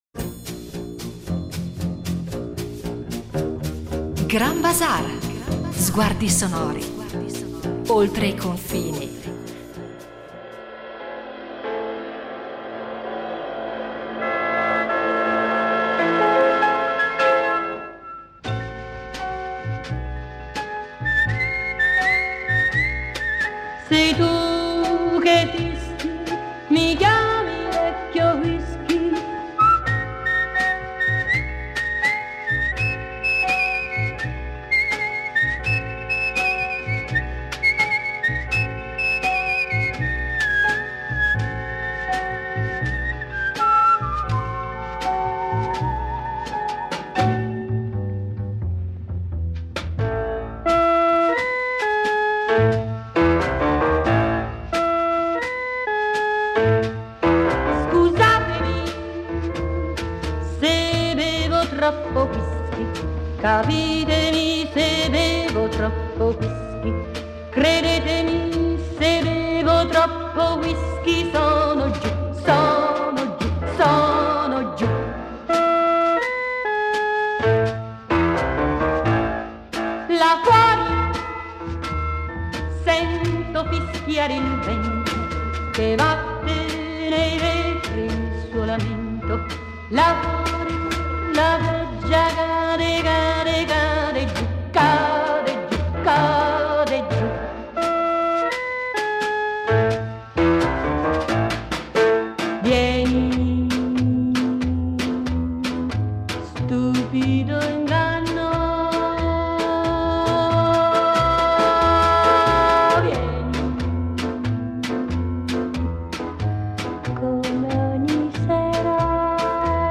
I numerosi ascolti mettono in luce le qualità delle sue composizioni: frizzanti, imprevedibili, dai ritmi sincopati, jazz e swing.